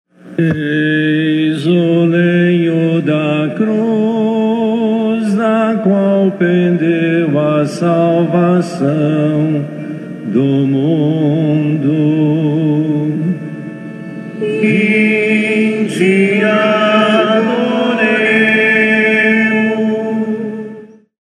Às 15h, conhecida como a “hora da agonia”, foi realizada a Celebração da Paixão do Senhor no Santuário de Fátima.
A cada parte descoberta, ele entoava: “Eis o lenho da Cruz, do qual pendeu a salvação do mundo.”